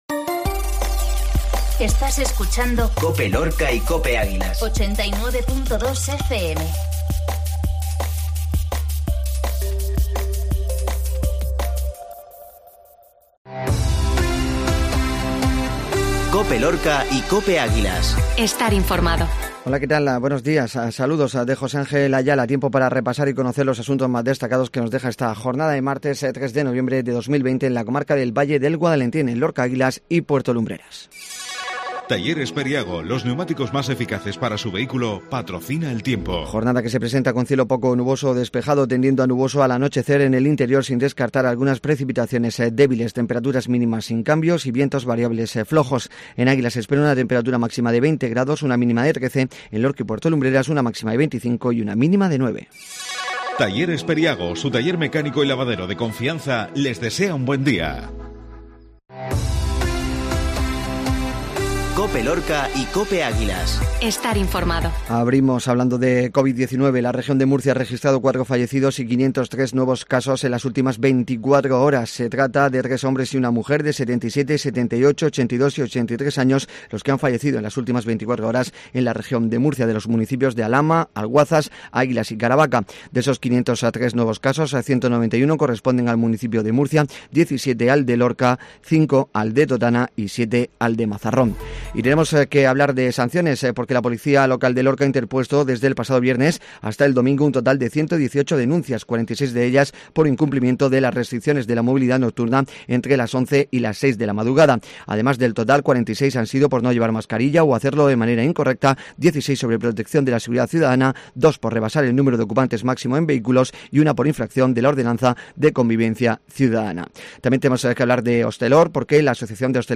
INFORMATIVO MATINAL MARTES